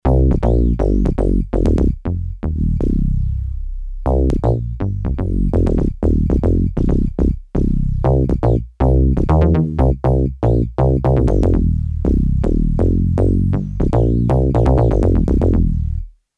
Bass: bass dual + balancing
bass-dual-dx21.mp3